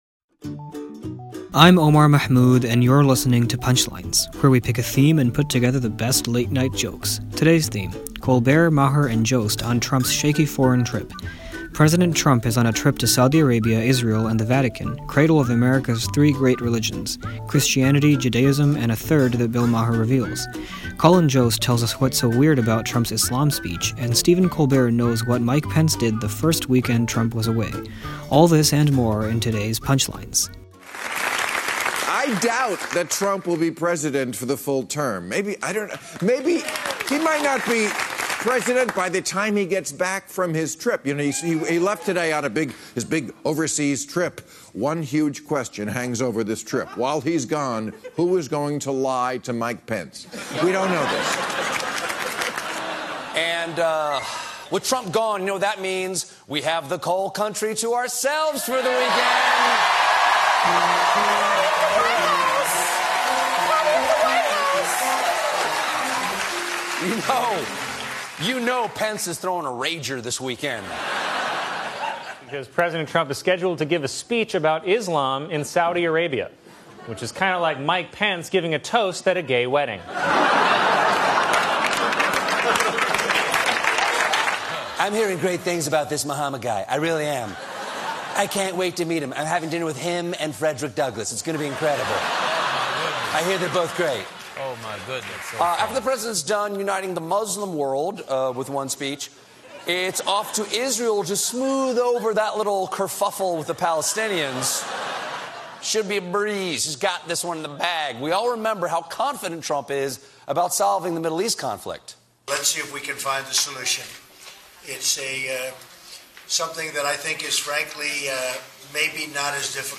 The late-night comics take on his Saudi speech and potential trouble in Israel.